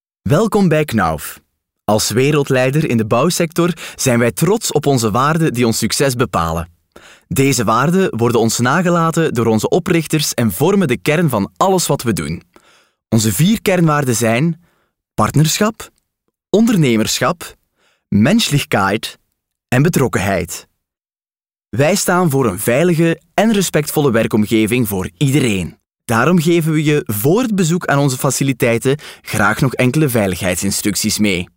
Commercial, Young, Urban, Versatile, Friendly
E-learning